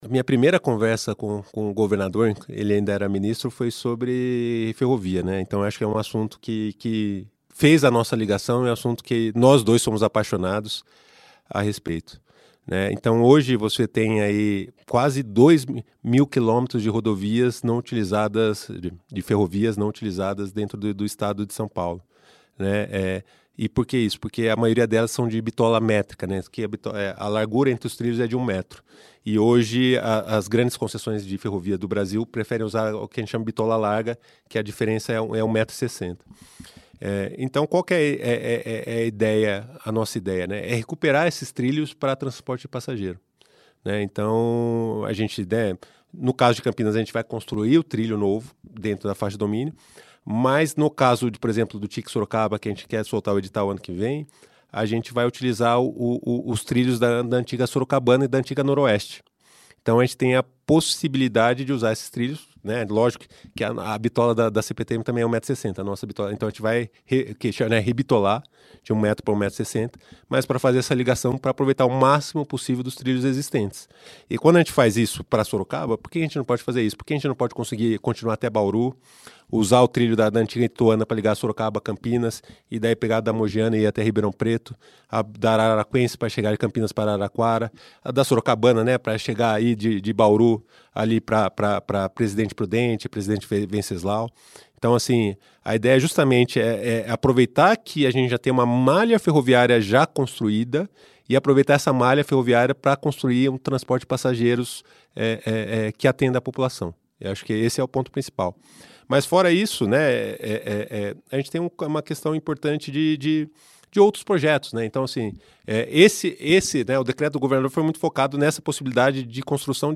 São Paulo deve aproveitar ferrovias abandonadas em plano de expansão de R$ 194 bilhões e trocar largura (bitola) em trilhos – OUÇA ENTREVISTA